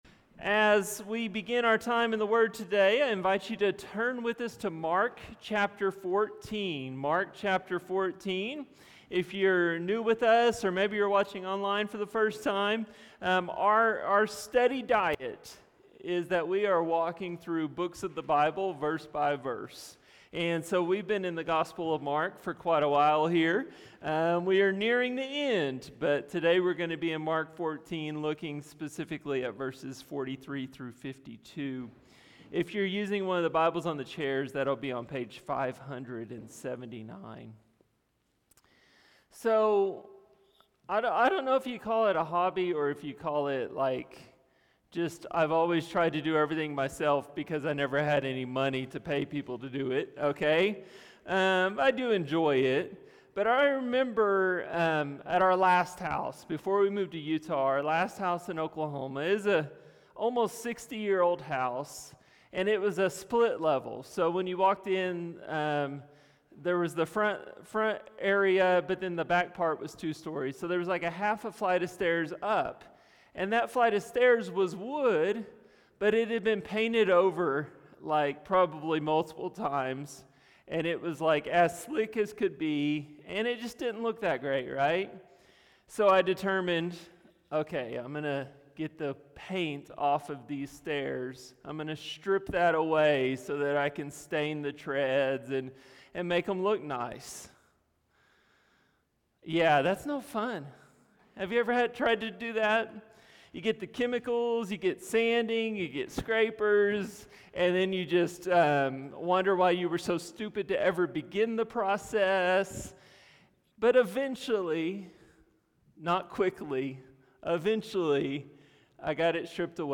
A message from the series "The Gospel of Mark."